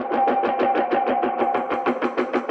RI_DelayStack_95-04.wav